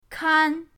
kan1.mp3